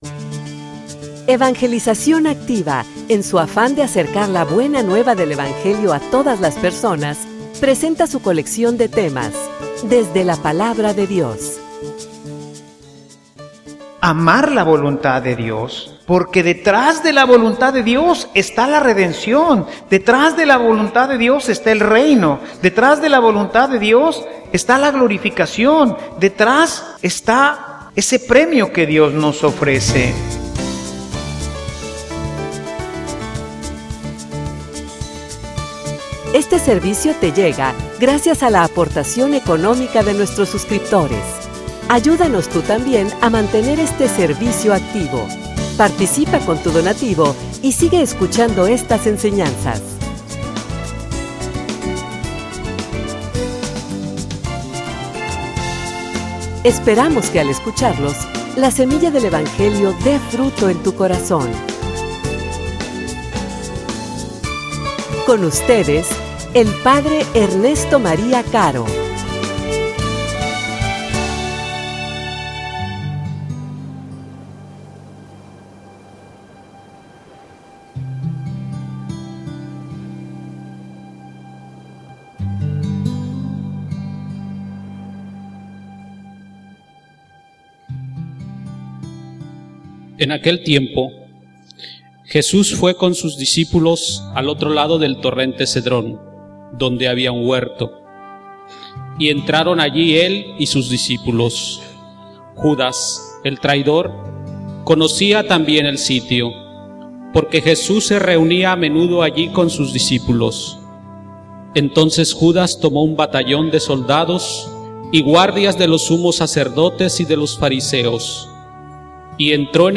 homilia_La_ensenanza_del_Viernes_Santo.mp3